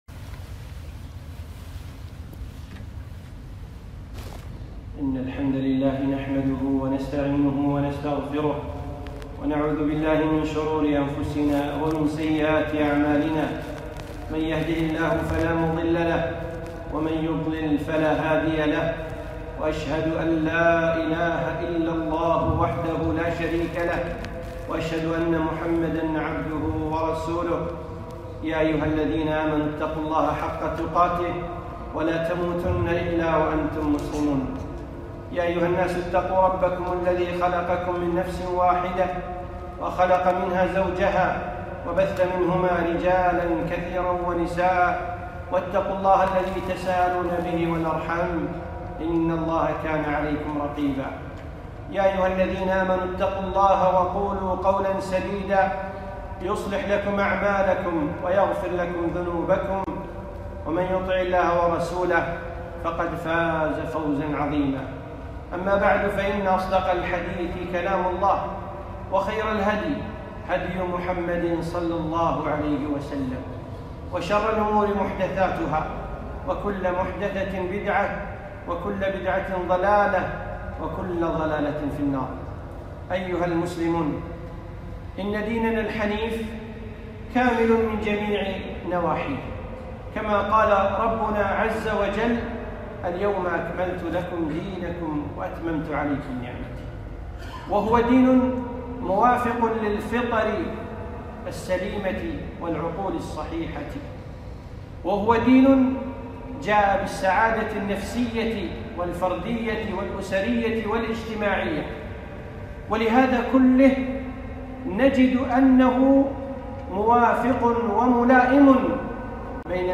خطبة - الأخـذ بالأسبـاب